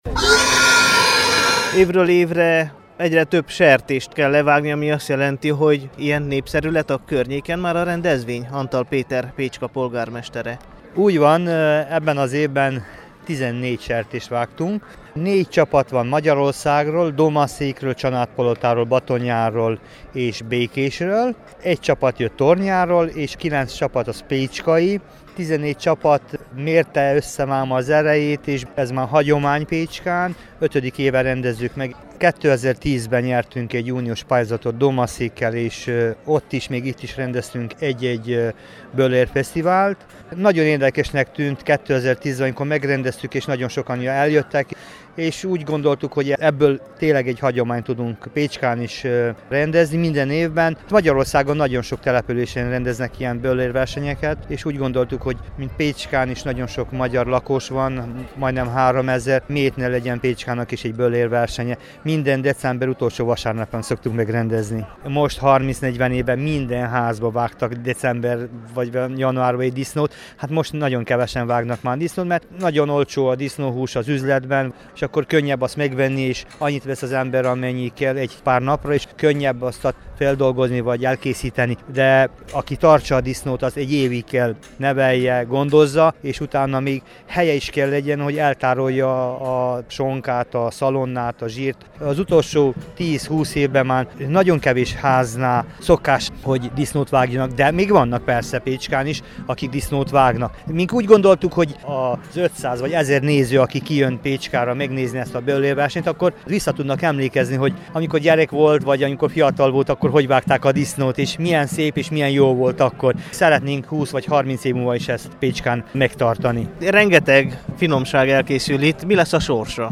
Az Arad megyei Pécskán szervezett hagyományos böllérfesztiválon is csak a hízóknak kötelező a részvétel, az érdeklődőket és a benevező csapatokat a kíváncsiság, no meg a jó hangulat vezérli ki. Idén, az óév utolsó vasárnapján több mint egy tucat böllércsapat gyűlt össze, hogy ki-ki a saját tudása és receptje szerint levágja, feldolgozza és elkészítse az önkormányzat által felajánlott sertéseket.
pecskai_disznotor_2014.mp3